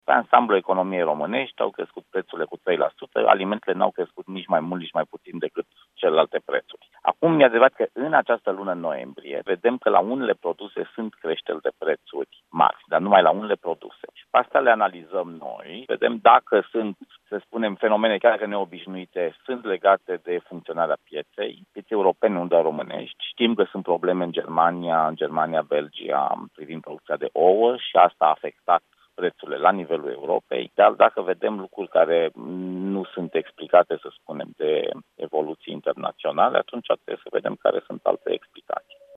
Președintele Consiliului Concurenței, Bogdan Chirițoiu, într-un interviu pentru Europa FM: